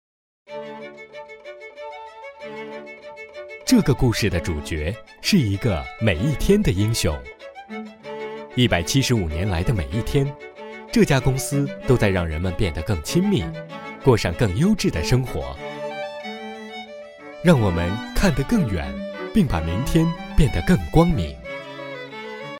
Warm, bright, compellent, smooth, professional.
Sprechprobe: Sonstiges (Muttersprache):